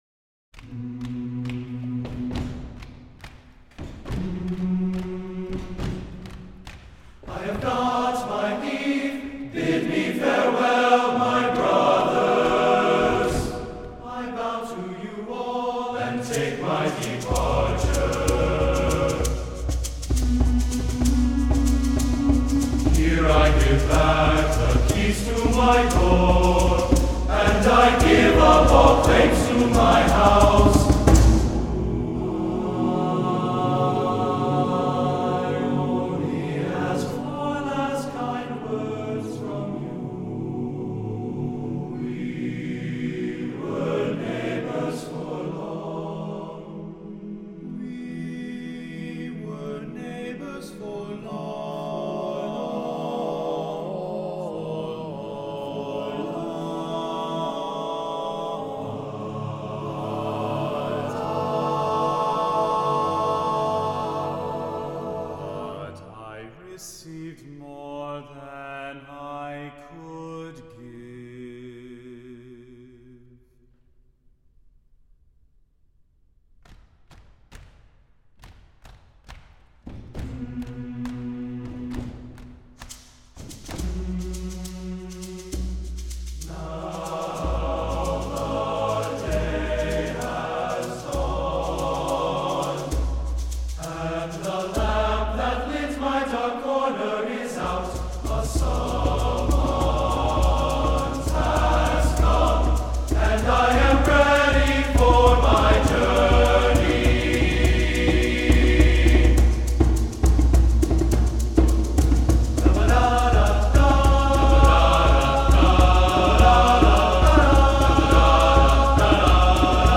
Voicing: TTBB a cappella